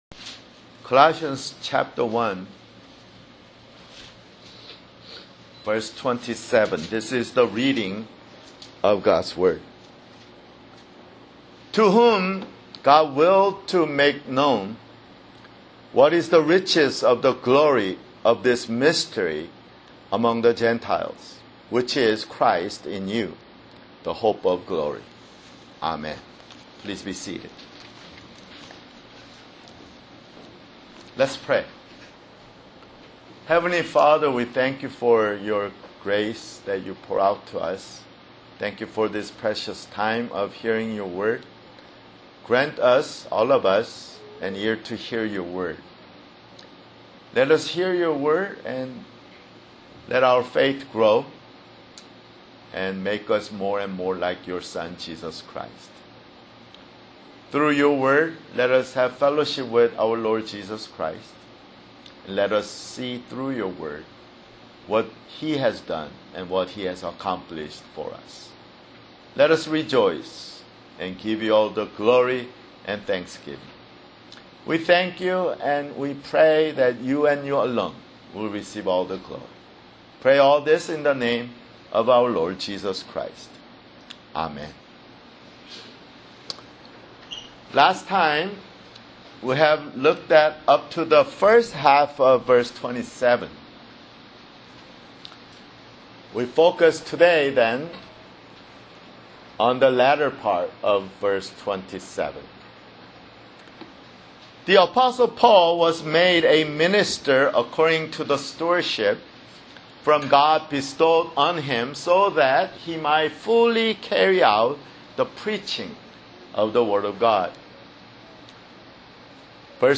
[Sermon] Colossians (38)